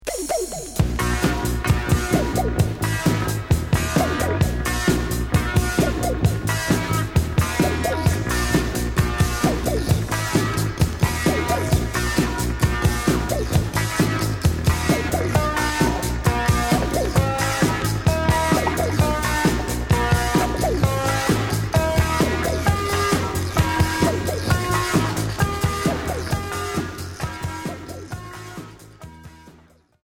Rock experimental